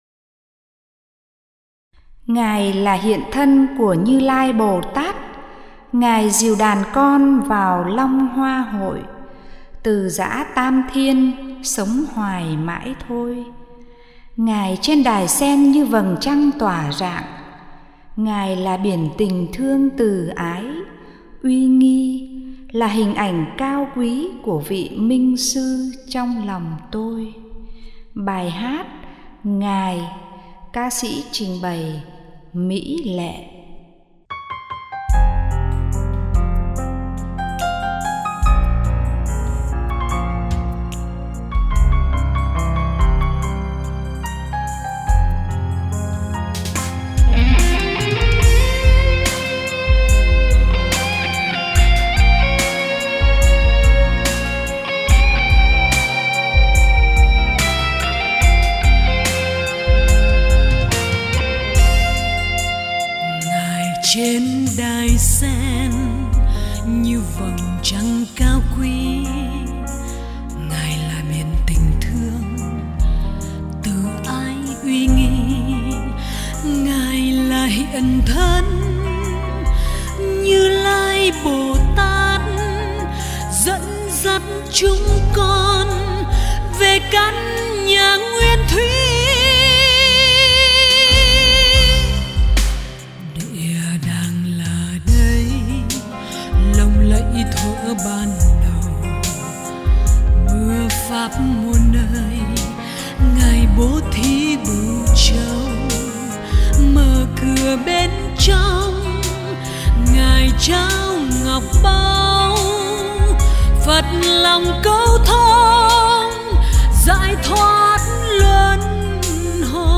Category: Tân Nhạc